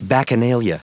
Prononciation du mot bacchanalia en anglais (fichier audio)